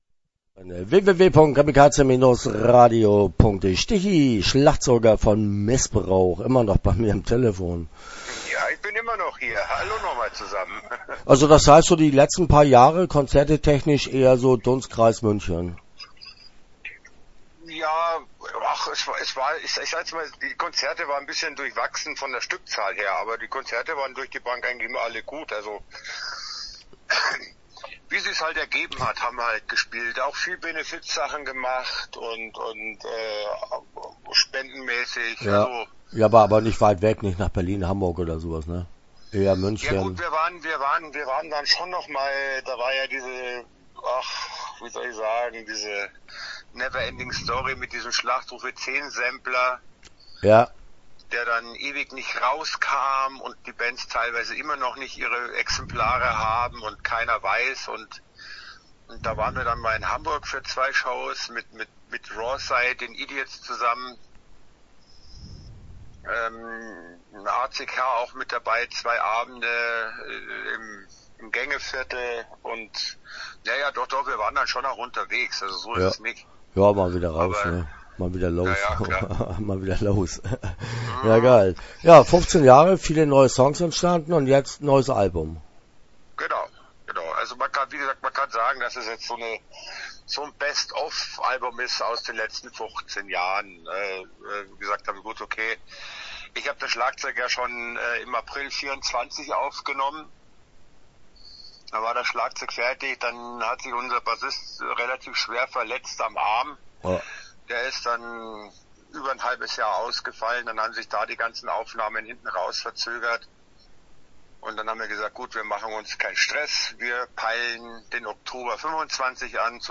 Missbrauch - Interview Teil 1 (9:30)